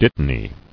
[dit·ta·ny]